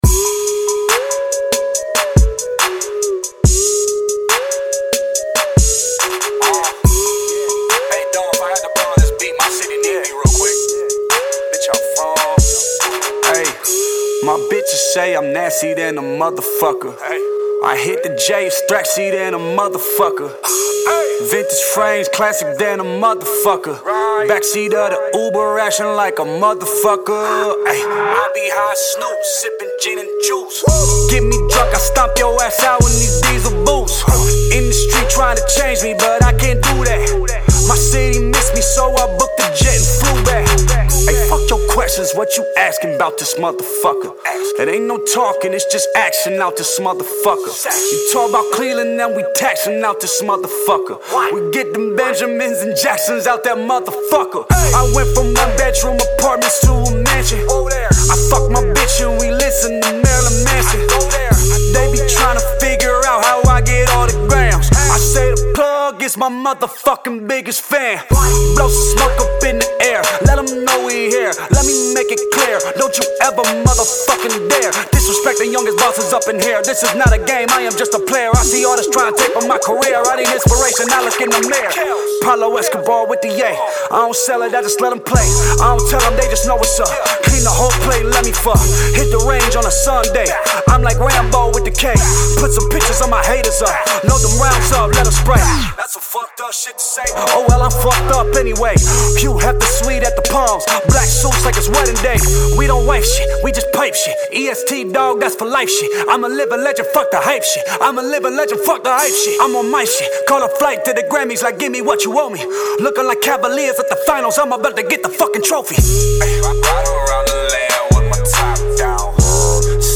NEW FREESTYLE